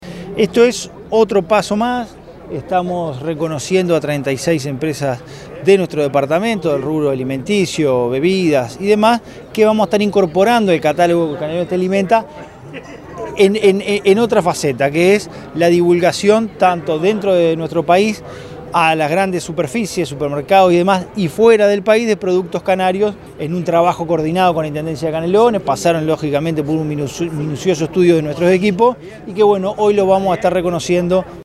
dr._esc._francisco_legnani_secretario_general_intendencia_canelones.mp3